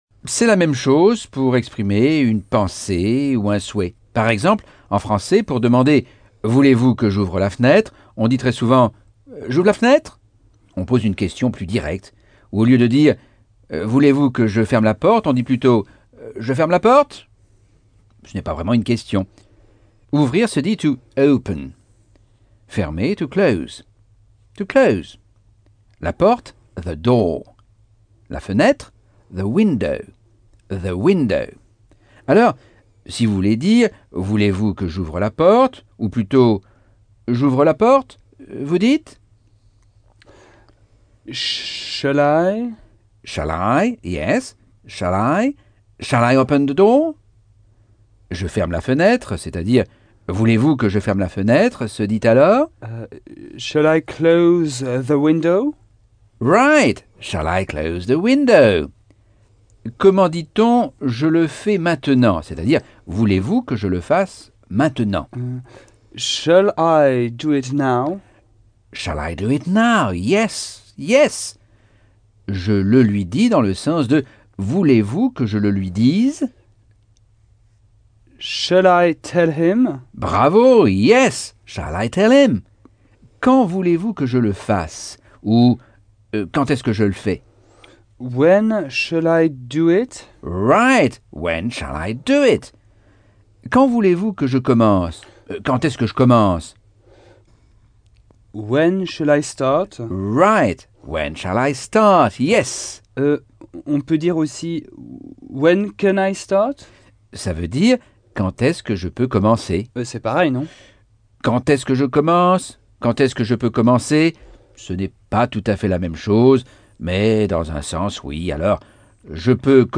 Leçon 8 - Cours audio Anglais par Michel Thomas - Chapitre 5